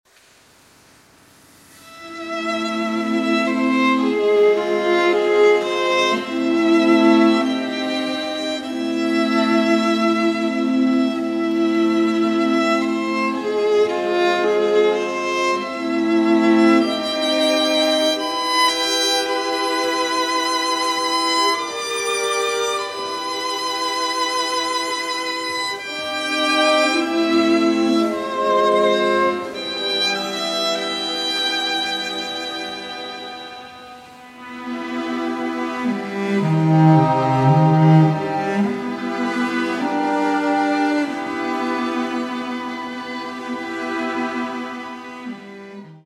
First Recordings, Live